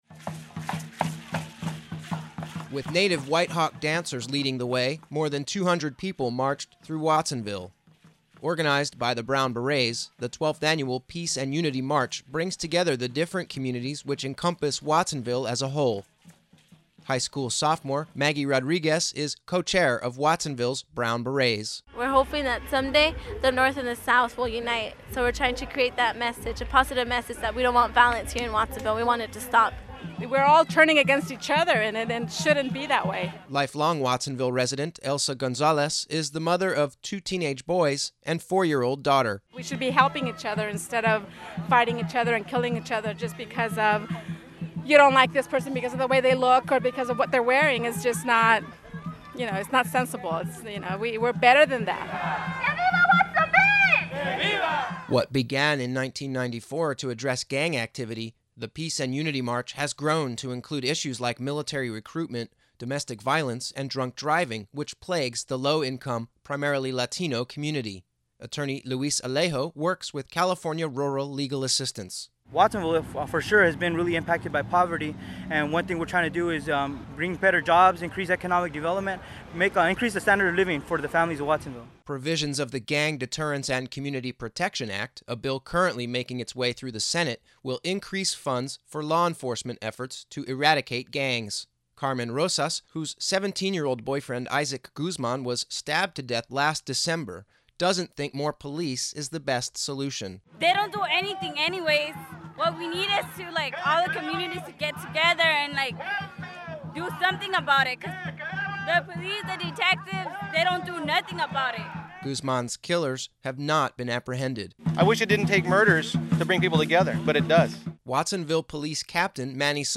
This story produced for Oct. 17 edition of Free Speech Radio News